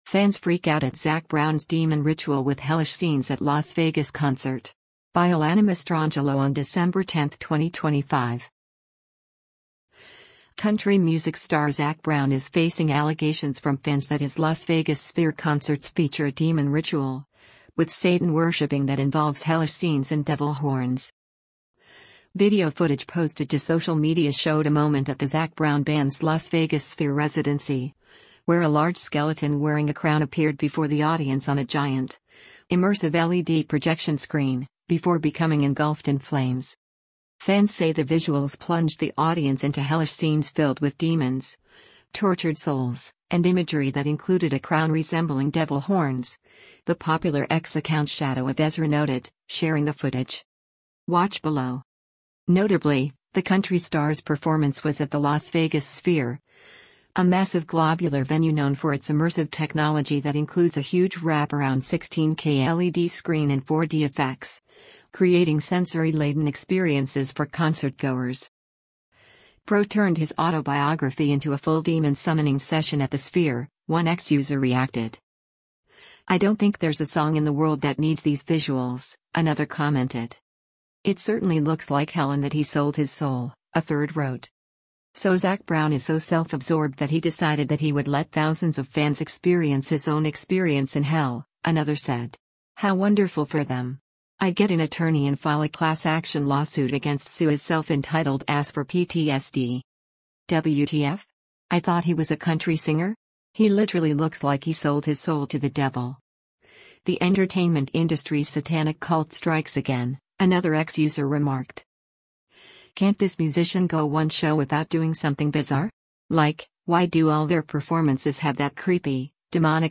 Country music